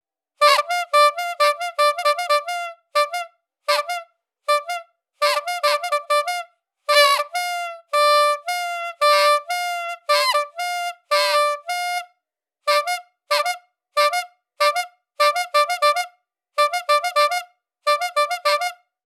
vintage bicycle horn
bicycle bike bulb bulb-horn bulbhorn cartoon circus clown sound effect free sound royalty free Voices